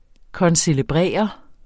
Udtale [ kʌnseləˈbʁεˀʌ ]